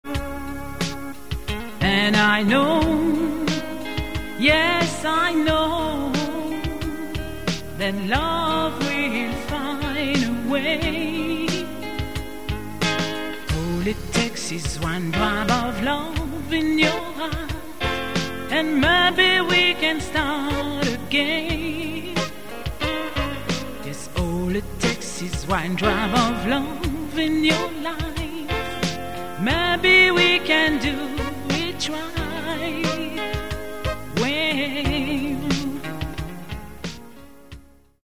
EXTRAIT PIANO-BAR